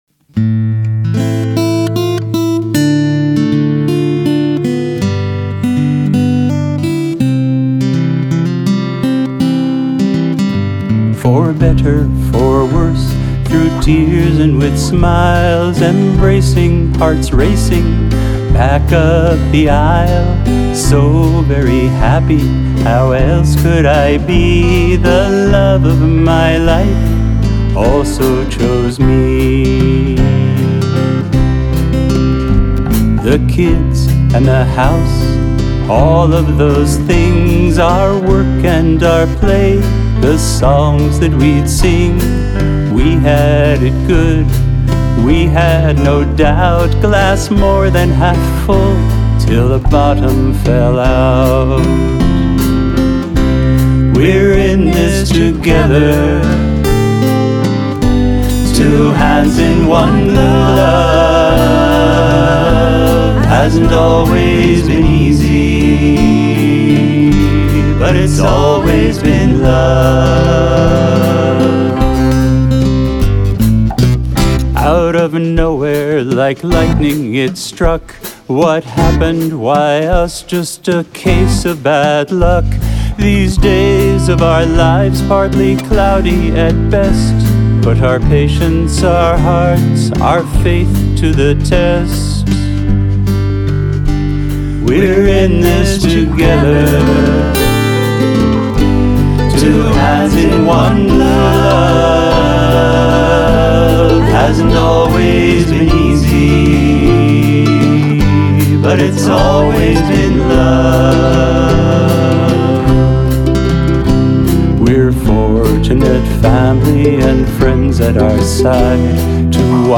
piano, vocal
violin